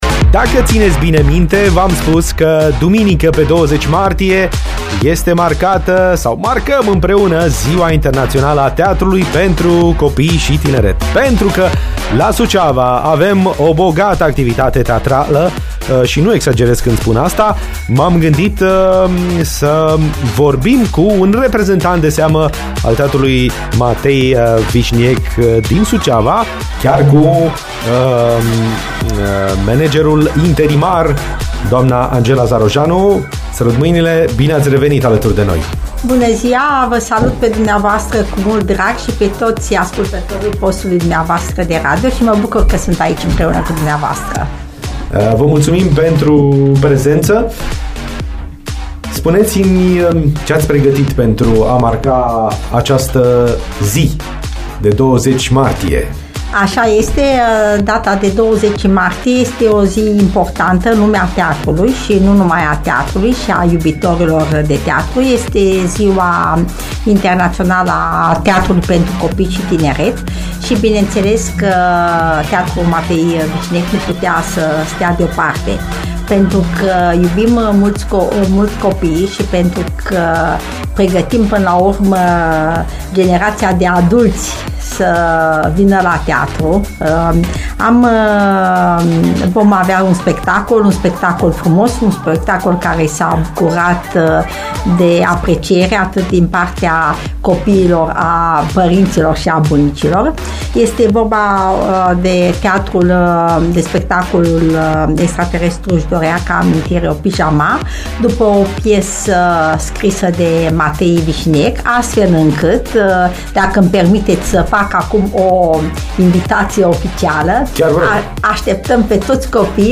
live despre Ziua Internațională a Teatrului